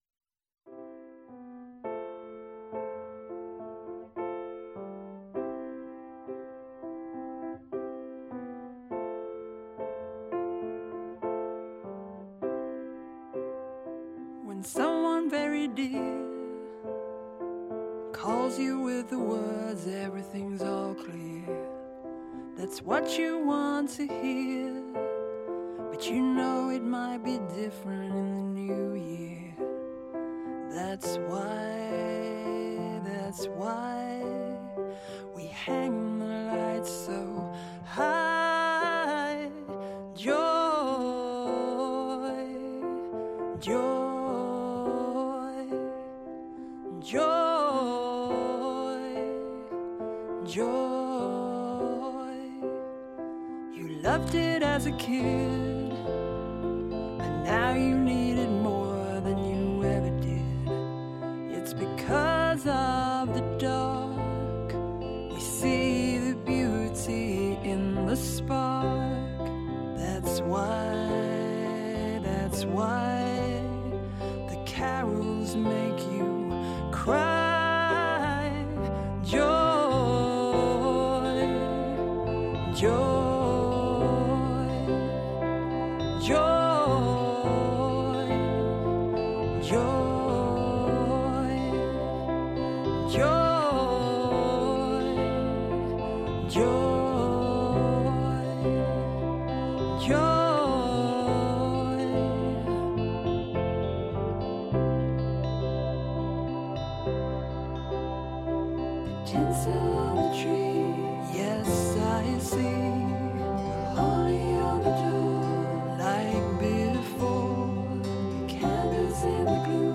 σε ένα χαλαρό “απογευματινό καφέ”.
ΜΟΥΣΙΚΗ